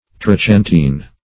Search Result for " trochantine" : The Collaborative International Dictionary of English v.0.48: Trochantine \Tro*chan"tine\, n. (Zool.) The second joint of the leg of an insect, -- often united with the coxa.